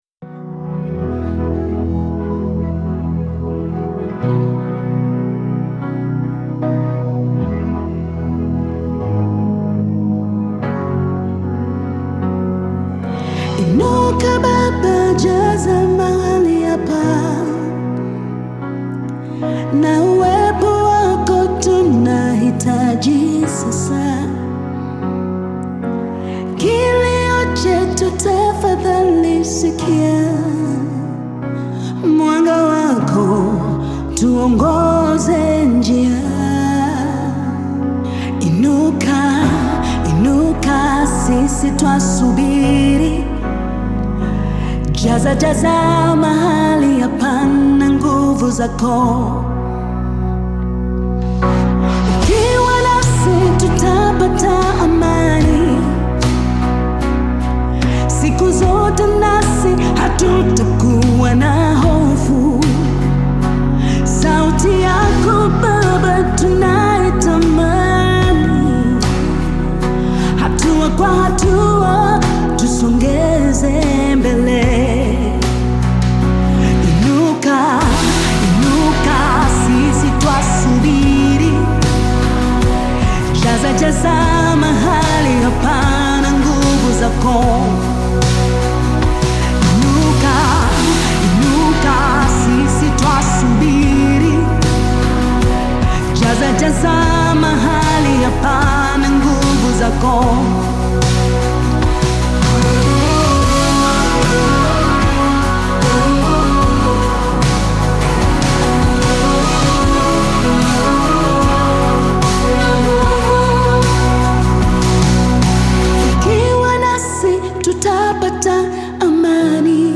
powerful praise song
gospel song